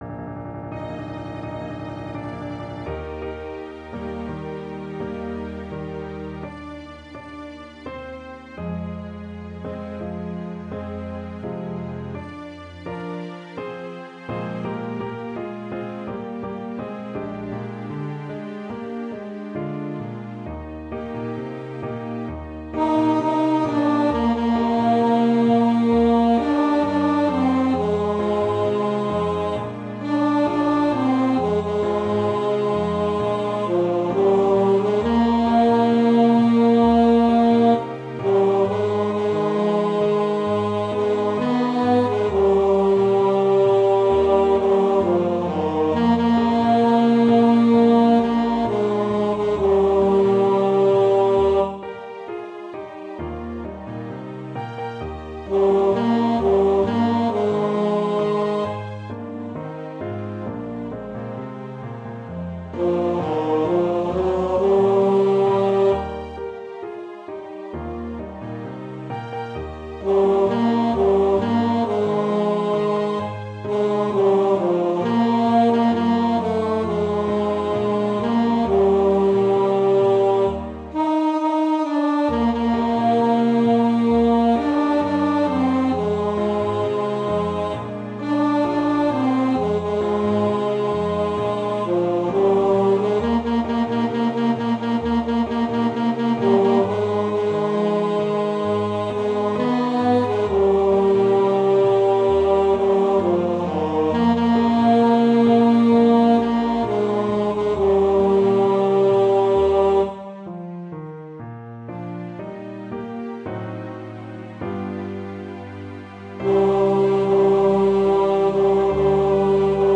Neopolitan Trilogy second tenor.mp3